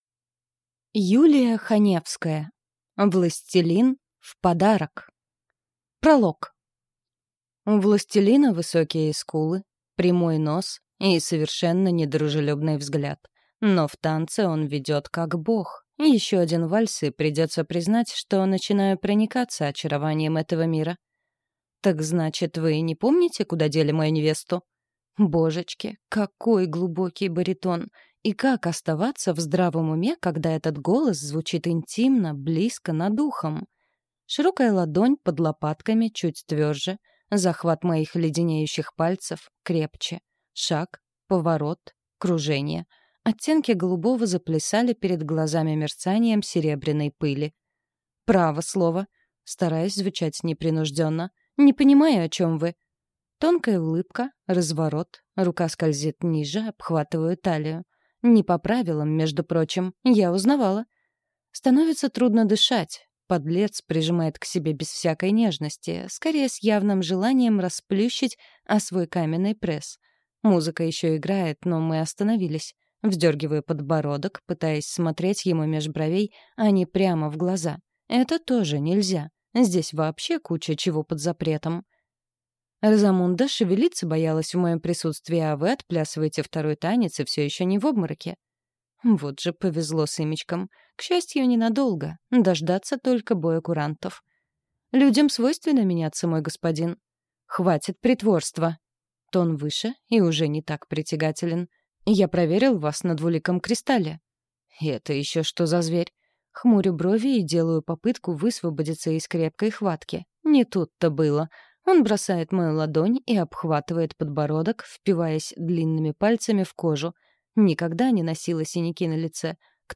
Аудиокнига Властелин в подарок | Библиотека аудиокниг
Прослушать и бесплатно скачать фрагмент аудиокниги